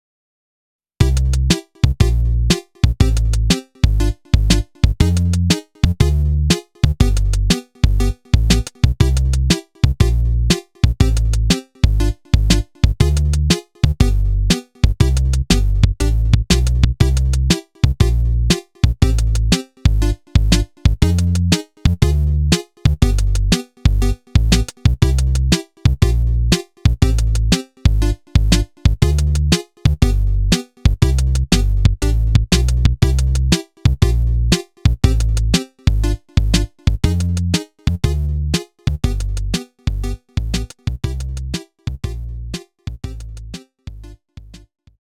Practice theme
Edited to loop